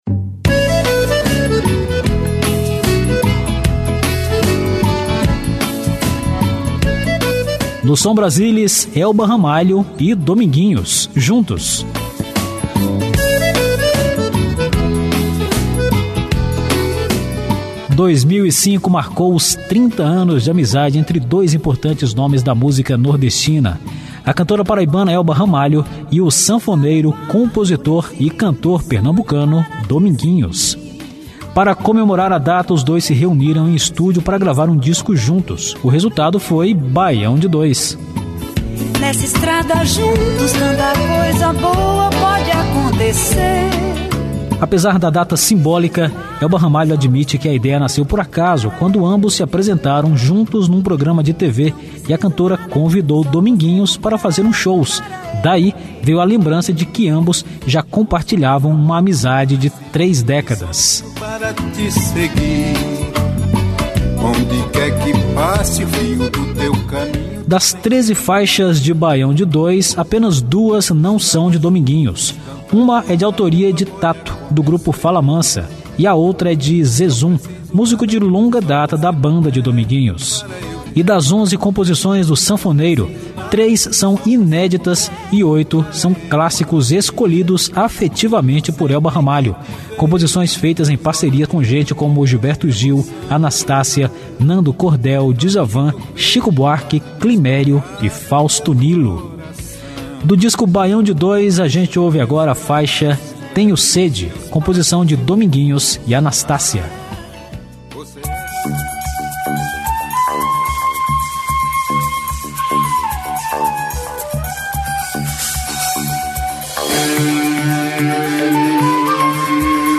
MPB
Ritmos nordestinos
Forró
Xote
Baião
Ao vivo